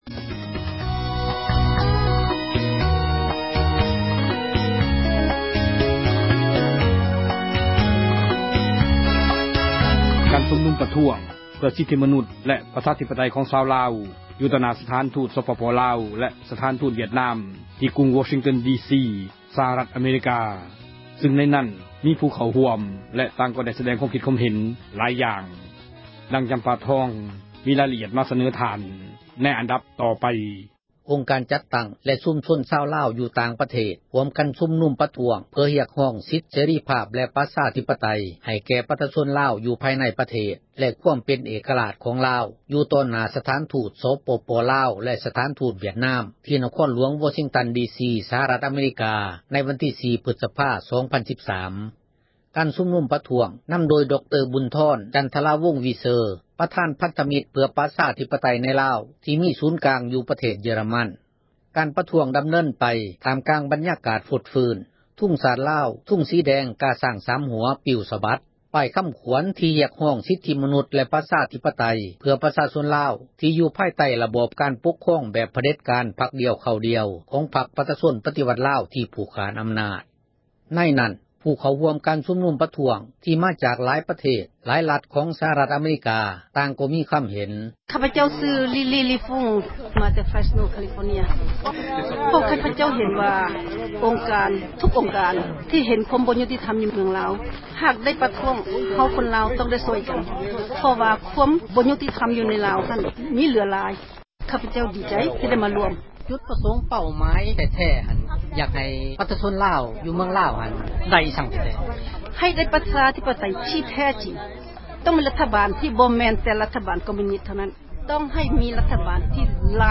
ການຊຸມນຸມ ປະທ້ວງ ເພື່ອ ສິດທິມະນຸດ ແລະ ປະຊາທິປະໄຕ ຂອງຊາວລາວ ຢູ່ຕໍ່ໜ້າ ສະຖານທູດ”ສປປລາວ” ແລະ ສະຖານທູດ ”ວຽດນາມ” ທີ່ກຸງ Washington DC ສະຫະຣັຖ ອະເມຣິກາ ຊຶ່ງໃນນັ້ນ ມີຜູ້ເຂົ້າຮ່ວມ ແລະ ຕ່າງກໍໄດ້ ສະແດງ ມີຄວາມຄິດ ຄວາມເຫັນ ຫລາຍຢ່າງ.